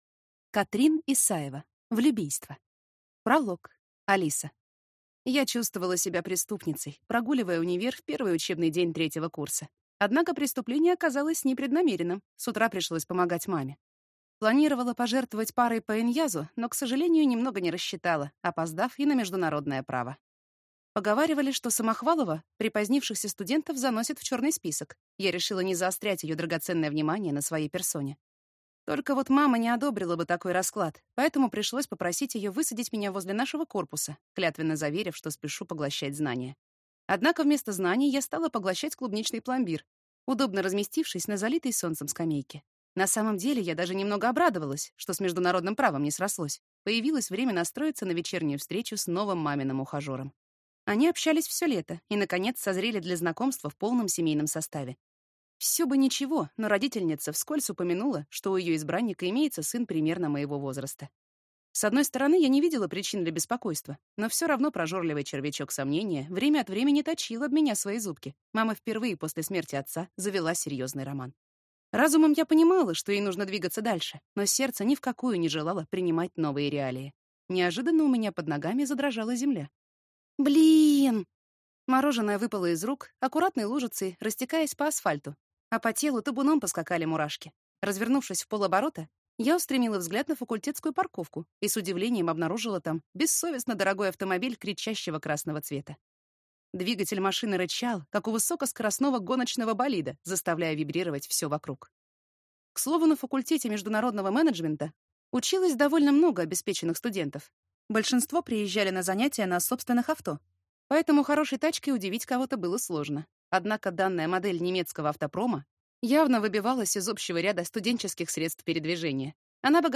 Аудиокнига Влюбийство | Библиотека аудиокниг